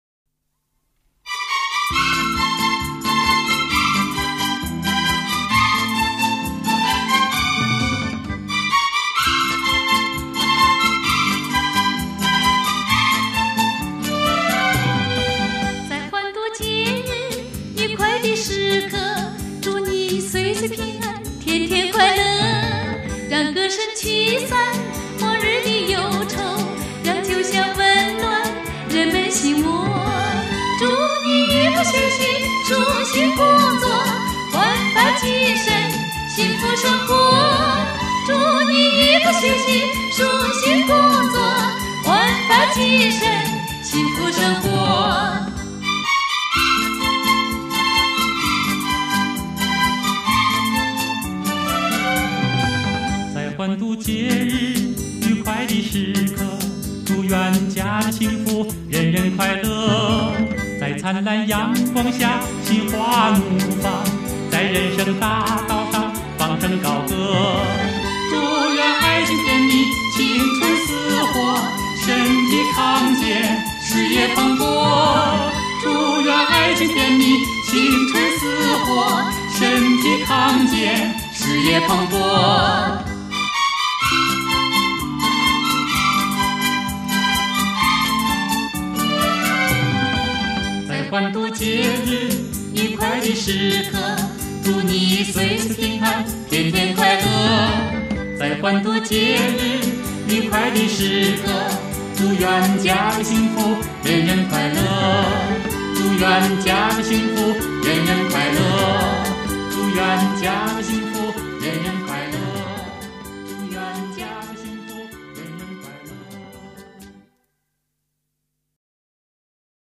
二人的合唱由其和谐、动听、极具魅力成为八十年代以来二人组合的黄金搭档。